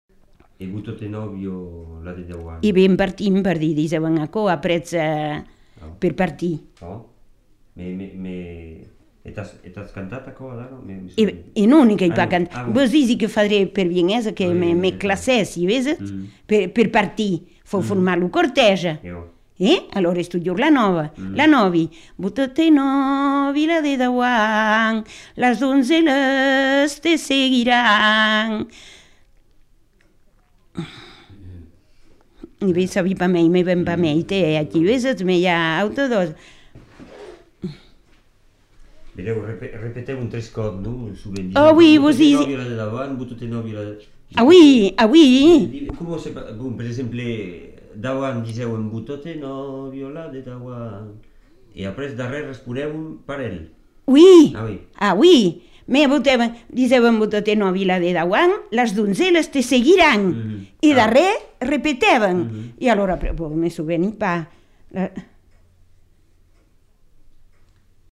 Lieu : Tonneins
Genre : chant
Effectif : 1
Type de voix : voix de femme
Production du son : chanté